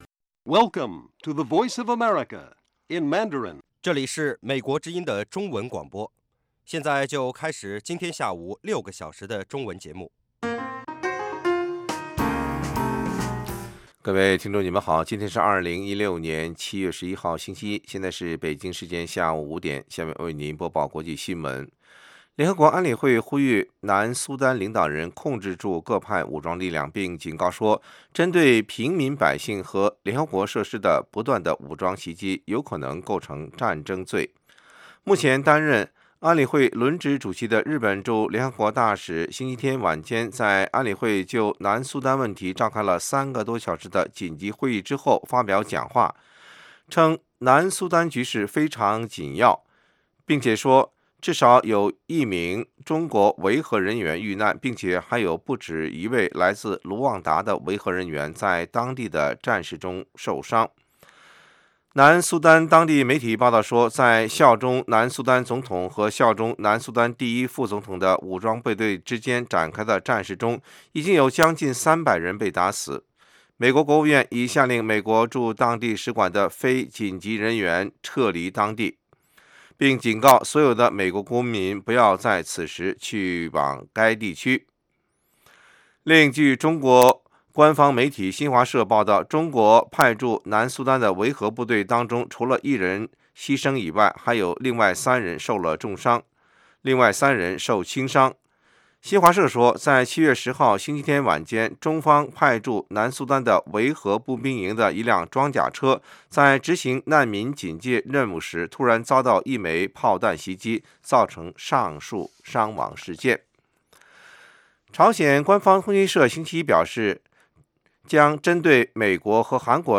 北京时间下午5-6点广播节目。 内容包括国际新闻和美语训练班（学个词， 美国习惯用语，美语怎么说，英语三级跳， 礼节美语以及体育美语）